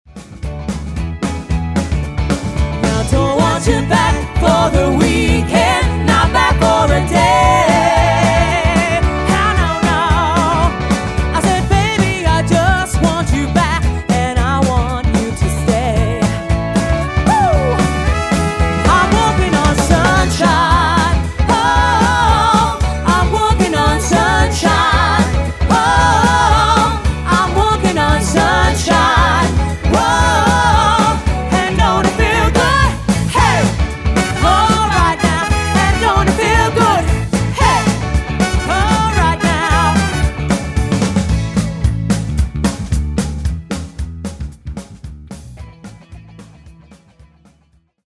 sophisticated and energetic band
Demo’s